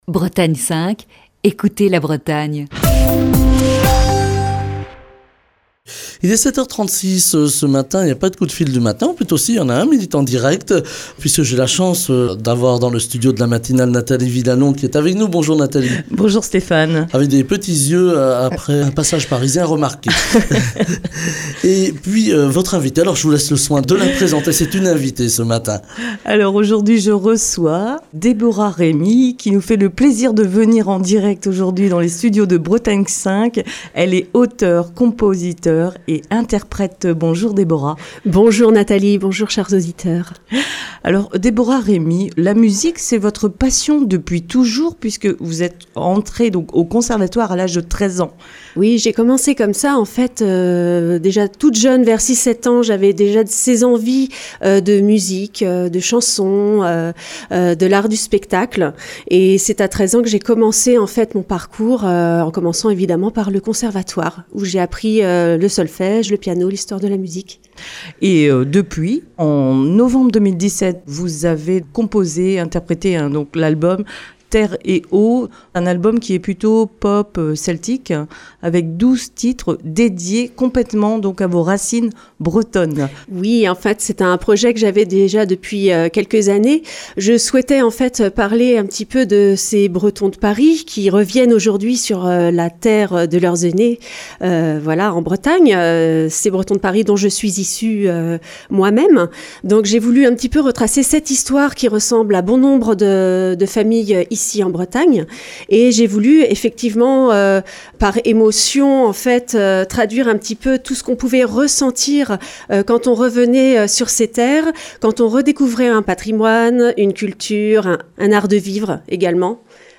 Une fois n'est pas coutume, l'invitée du coup de fil du matin est en studio ce matin.